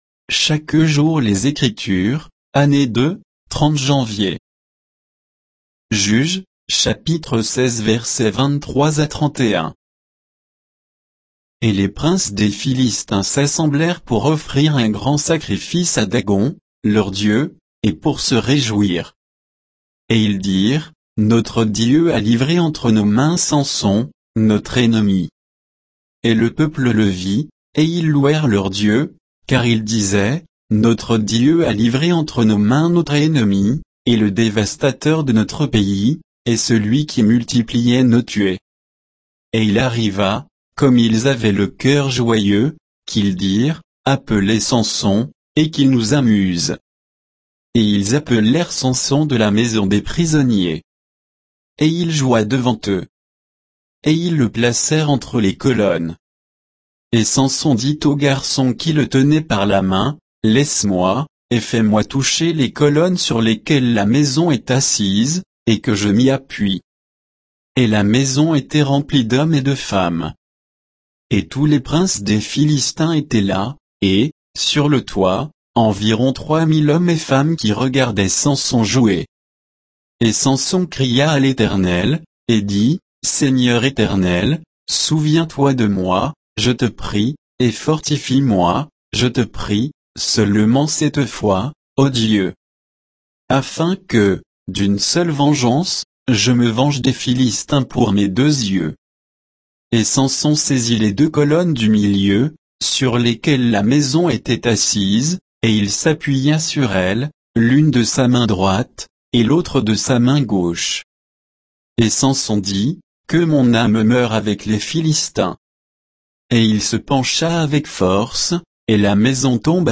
Méditation quoditienne de Chaque jour les Écritures sur Juges 16